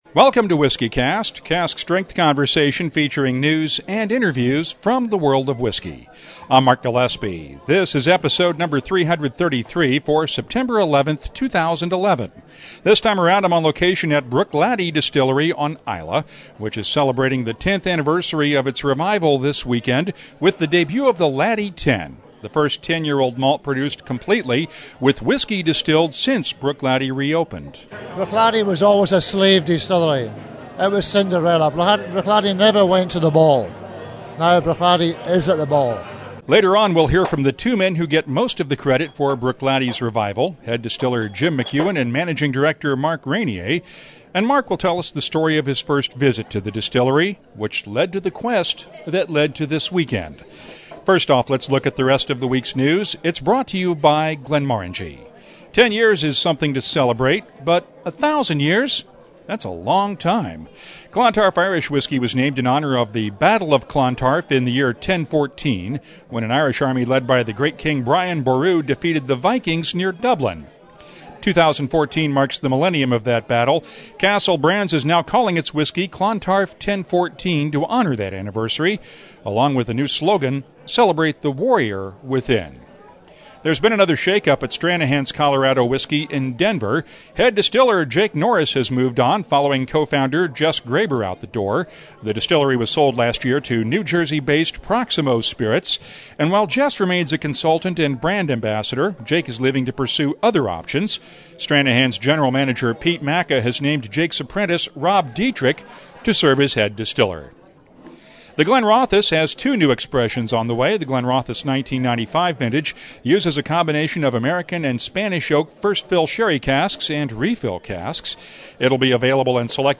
Bruichladdich celebrates the 10th anniversary of its revival this weekend, and this week’s episode comes from the distillery on the shores of Loch Indall on Islay!